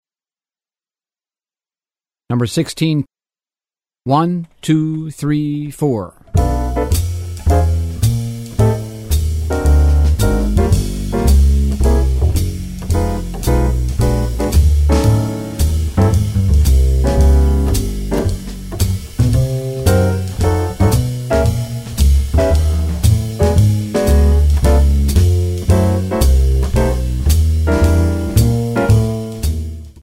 Voicing: Jazz Keyboard